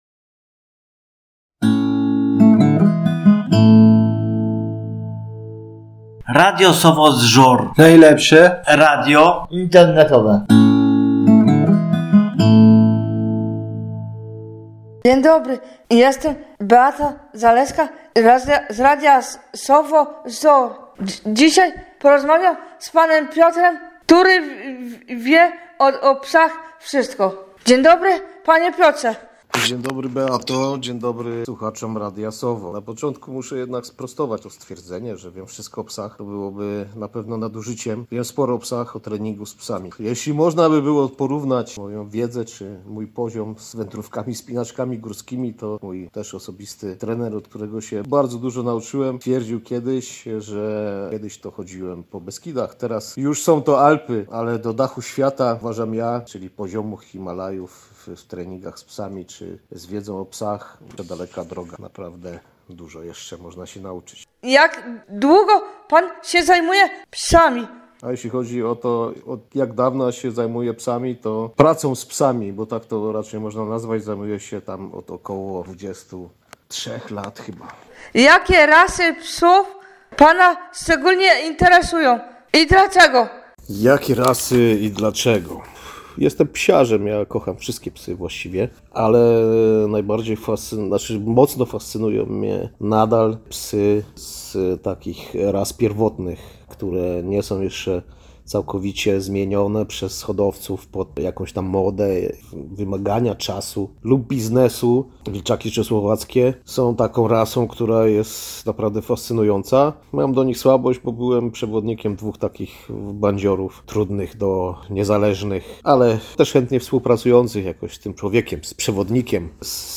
Nasz gość to ekspert, który podzieli się swoją wiedzą i doświadczeniem. Dowiecie się, że bycie odpowiedzialnym właścicielem zwierzaka to nie tylko przygoda, ale przede wszystkim obowiązek do którego trzeba dorosnąć.